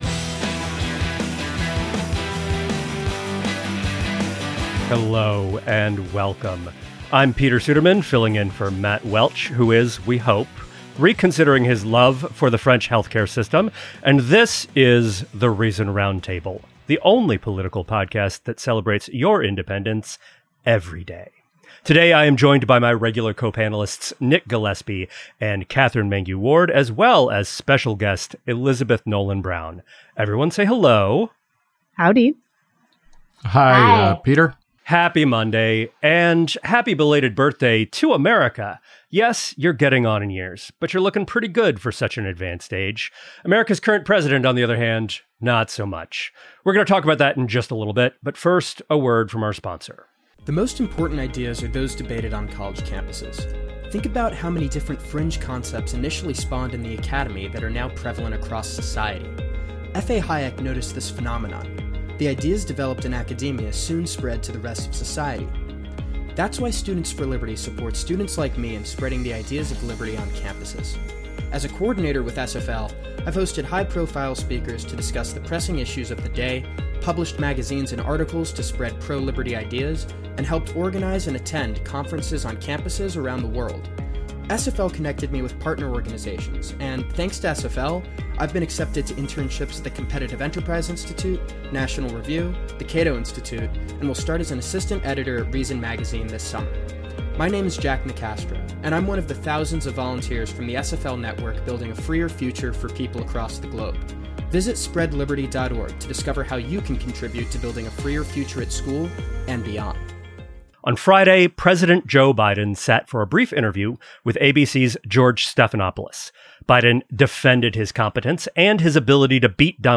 In this week's Reason Roundtable, we size up the fallout from the mainstream media's lag in coverage of Joe Biden's cognitive decline.